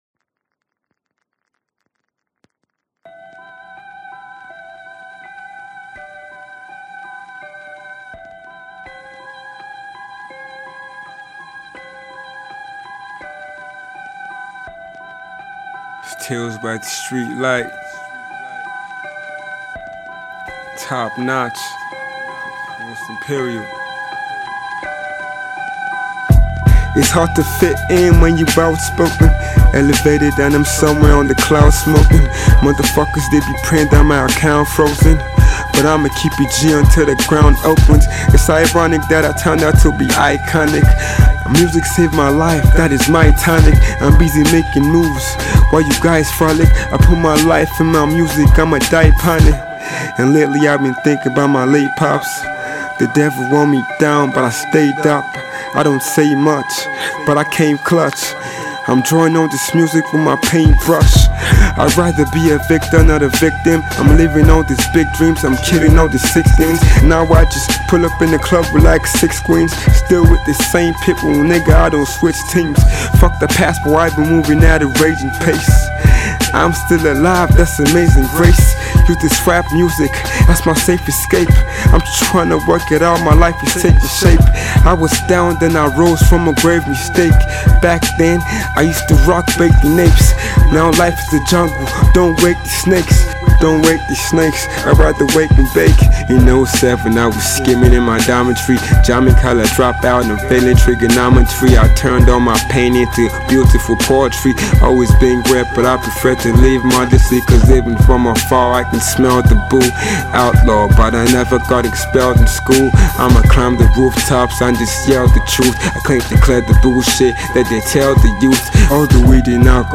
Hip-Hop
With an Emotion laden Voice